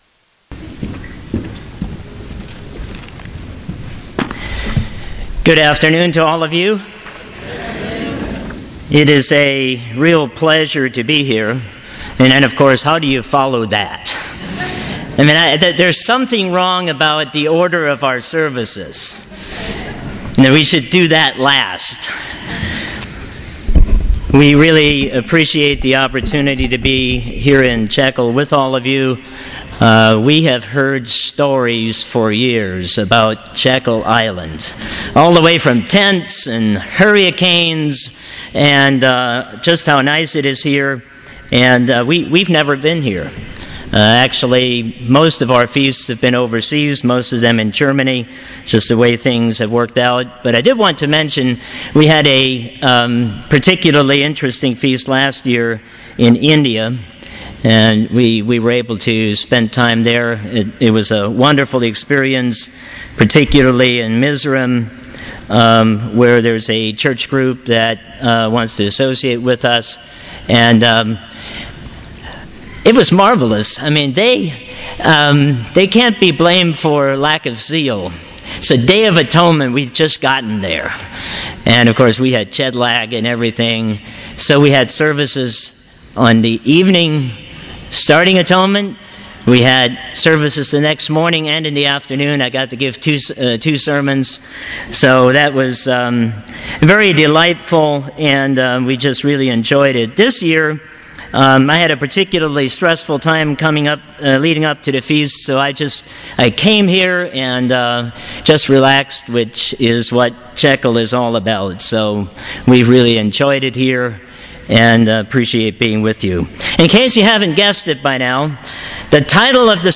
This sermon was given at the Jekyll Island, Georgia 2017 Feast site.